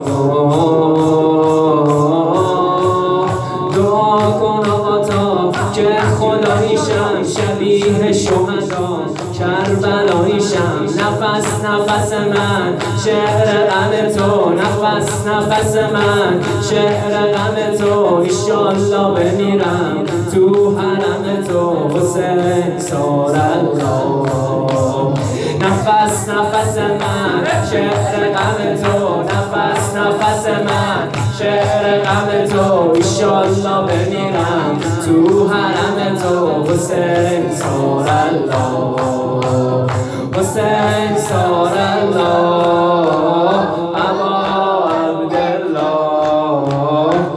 خیمه گاه - شجره طیبه صالحین - نفس نفس من _ شور _ شهادت امام حسن